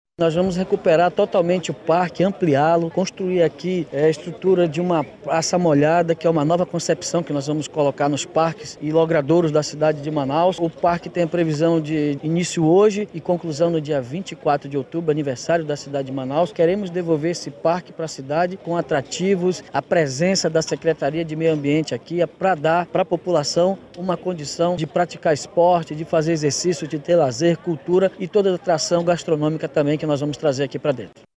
SONORA01_DAVI-ALMEIDA.mp3